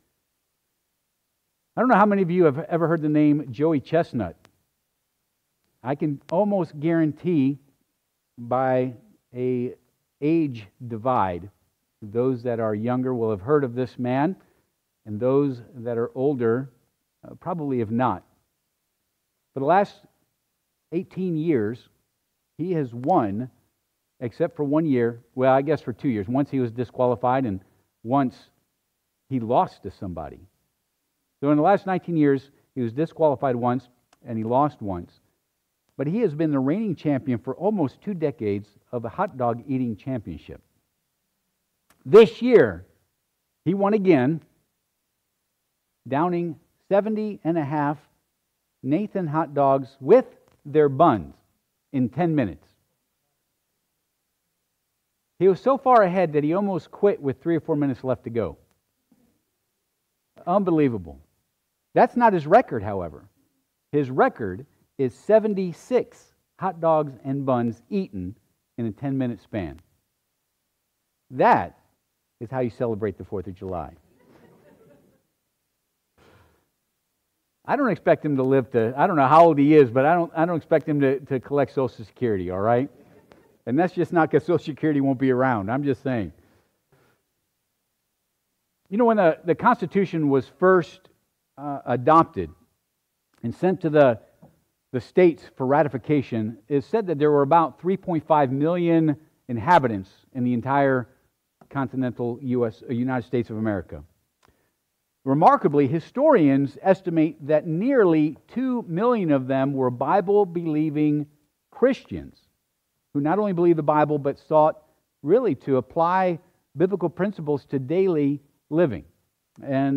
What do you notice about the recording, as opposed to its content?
Ps. 33:12 Service Type: Sunday AM Topics: Patriotism « The Period & Work of the Judges